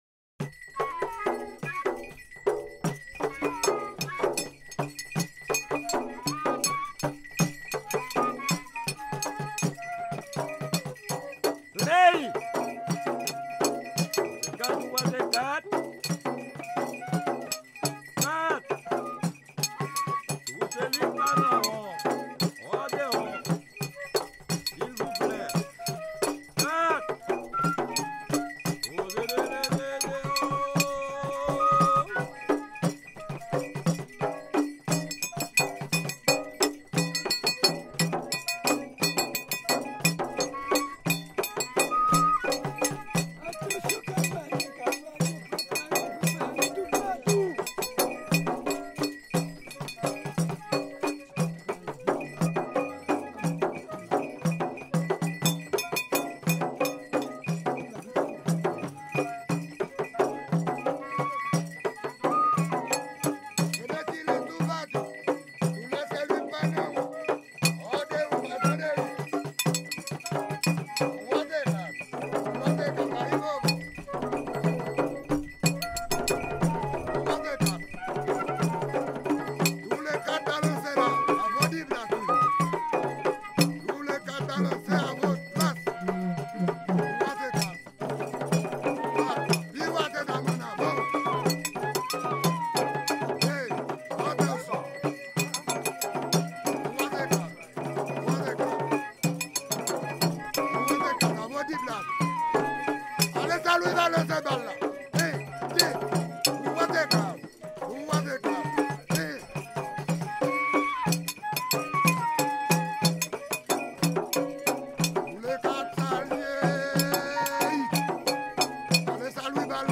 2022 CONTRE DANSE
FLOKLORE HAITIEN audio closed https